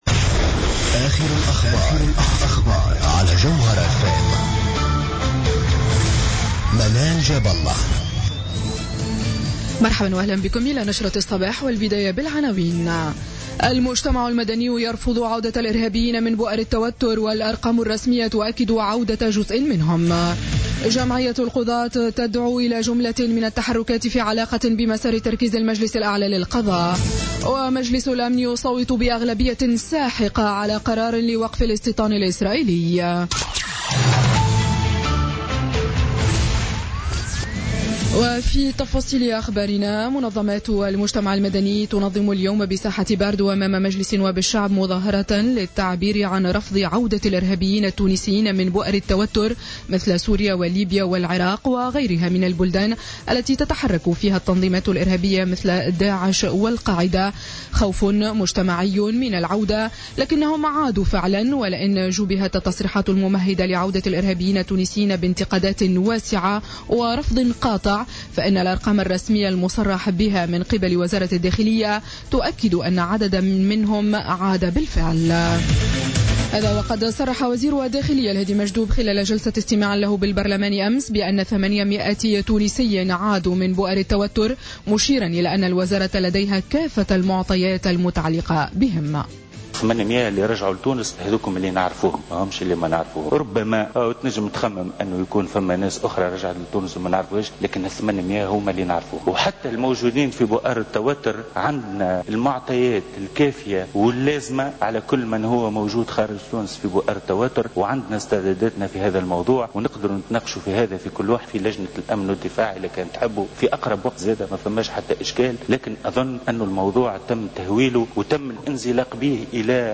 نشرة أخبار السابعة صباحا ليوم السبت 24 ديسمبر 2016